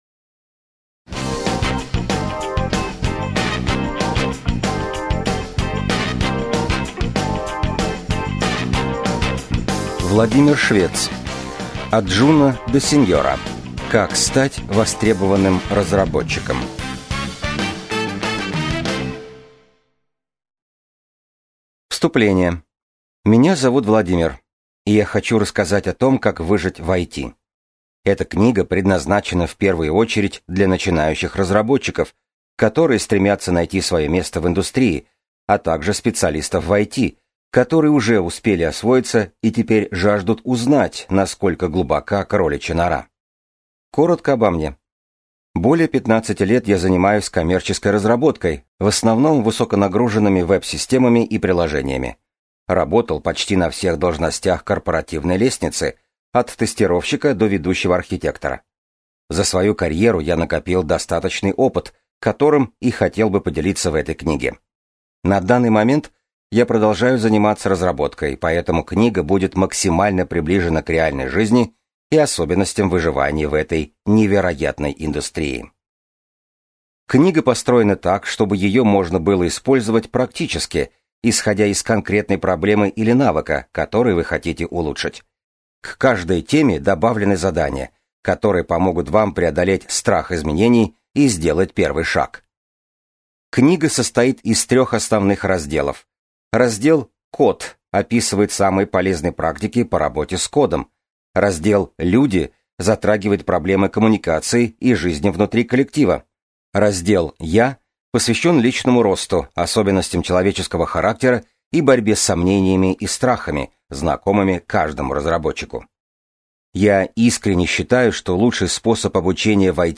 Аудиокнига От джуна до сеньора. Как стать востребованным разработчиком | Библиотека аудиокниг